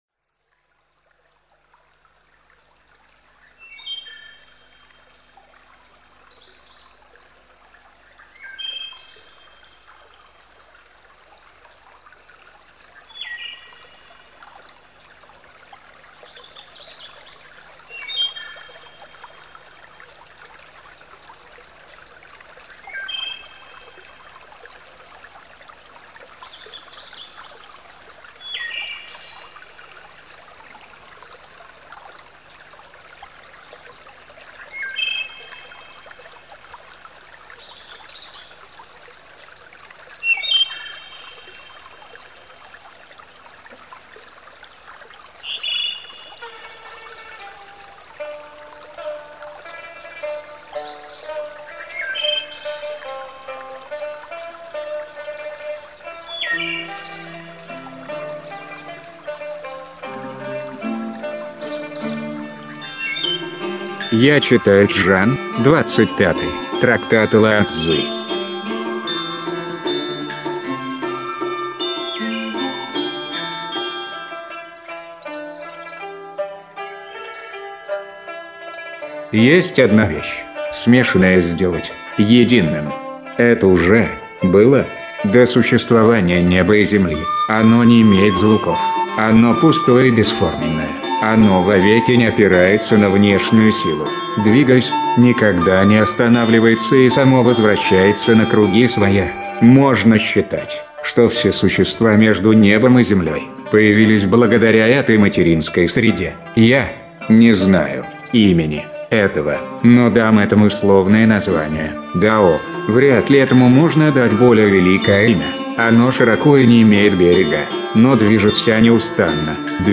Аудиокнига: Трактат о Дао и Дэ